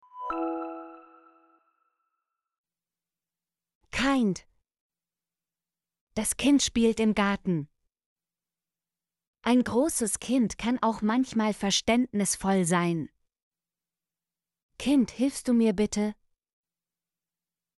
kind - Example Sentences & Pronunciation, German Frequency List